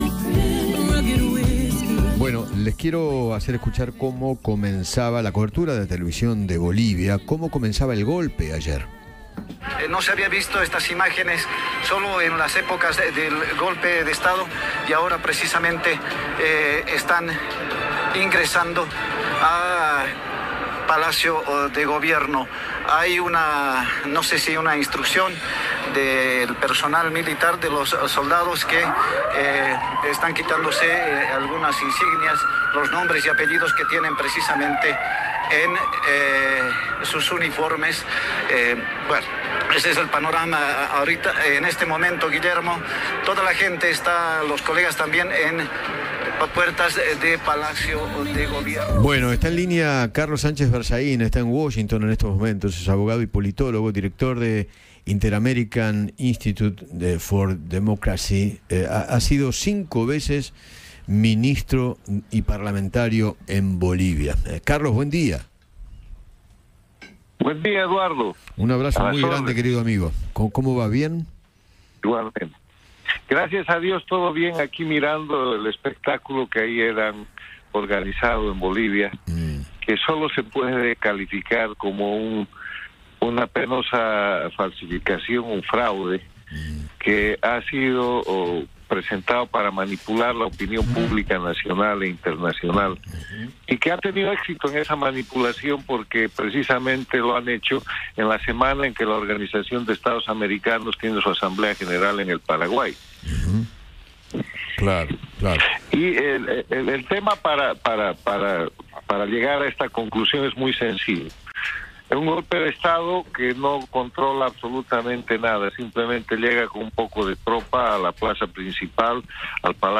Carlos Sánchez Berzaín, exministro y ex parlamentario de Bolivia, conversó con Eduardo Feinmann sobre la manifestación militar que hubo ayer por la tarde en contra del presidente Luis Arce.